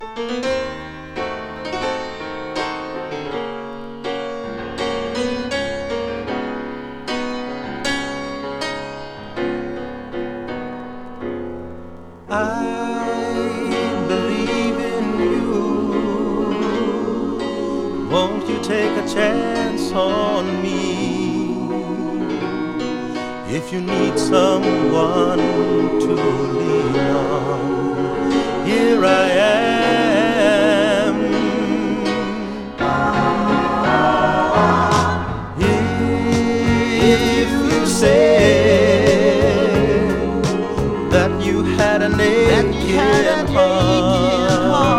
ポップ〜AOR風味漂う爽やかなハワイアン・アコースティック・デュオ
ふたりのボーカル、ハーモニーも魅力的。
Rock, Pop, Hawaii　USA　12inchレコード　33rpm　Stereo